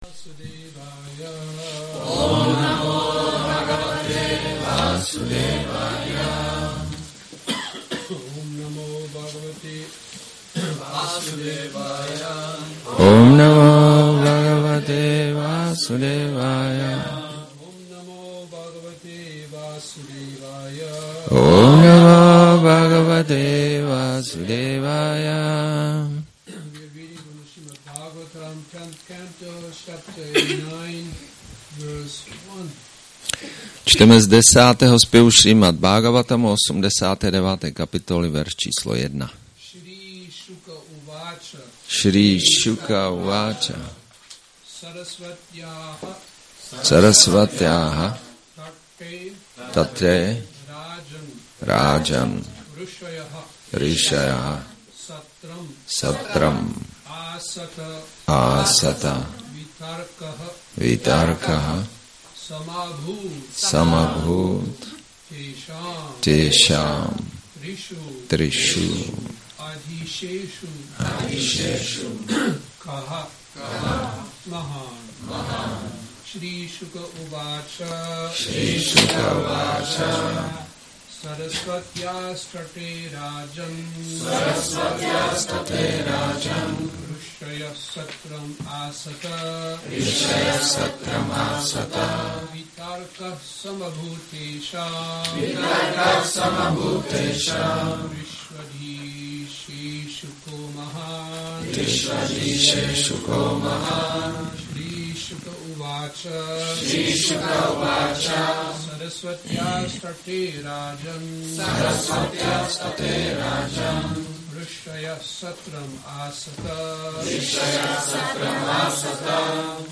Přednáška SB-10.89.1